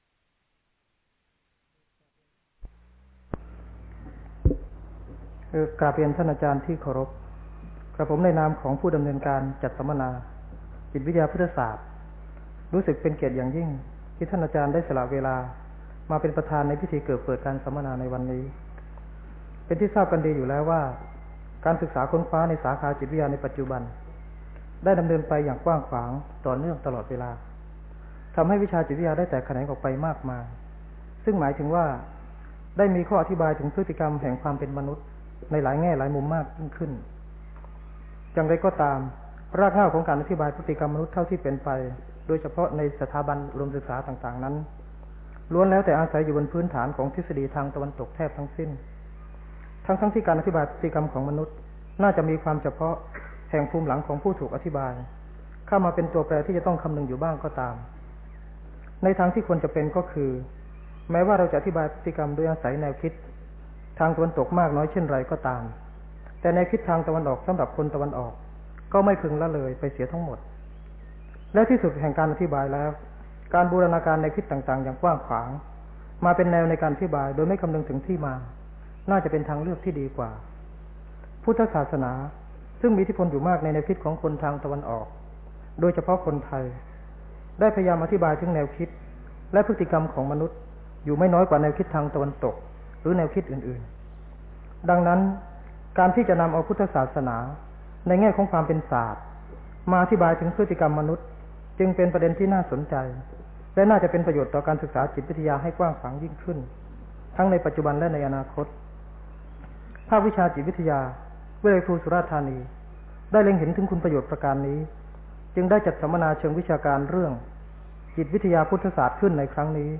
พระธรรมโกศาจารย์ (พุทธทาสภิกขุ) - บรรยายแก่คณะครูสอนจิตวิทยา ทั่วประเทศ จิตวิทยากับพุทธศาสนา